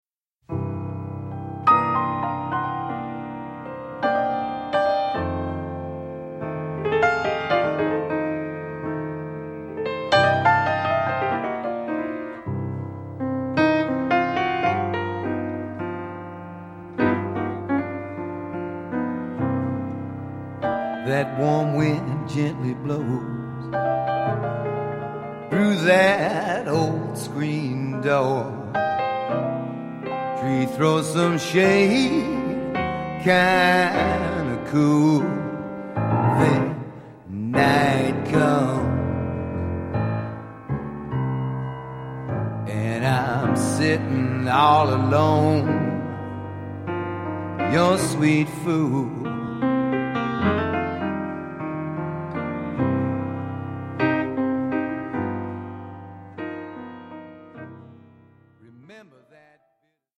This one is also live off the floor.